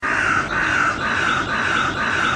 Waldgeräusch 5: Krähe / forest sound 5: crow